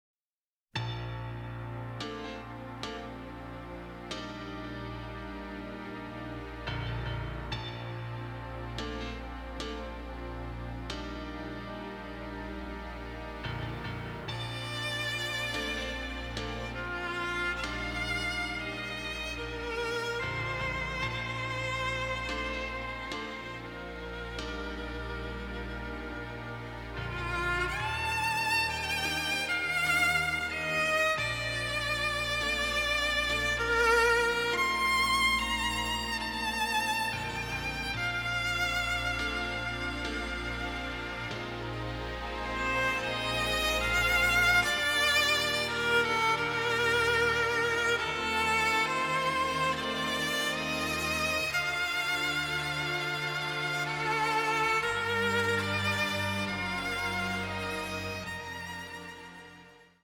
bizarre sci-fi score
electronic music